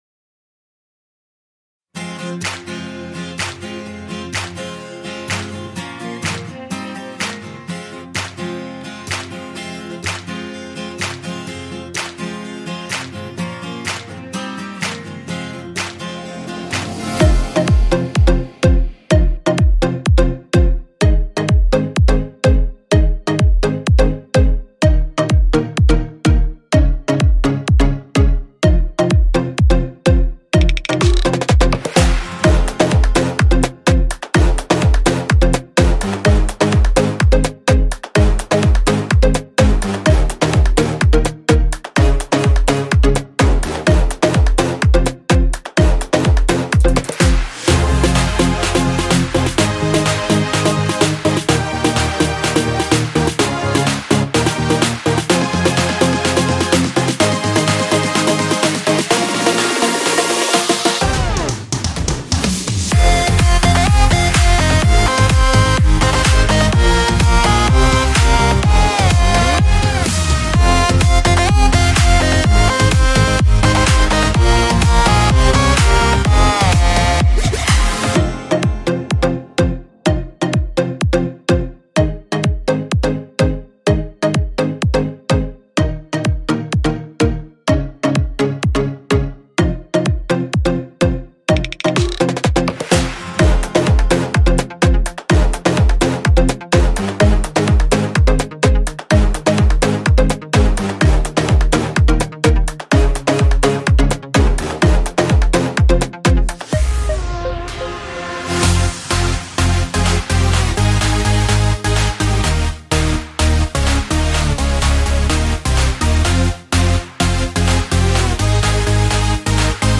בסוף הפלייבק יש מחיאות כפיים חוץ מזה הפלייבק טוב